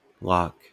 amer. IPA/lɑːk/
wymowa amerykańska?/i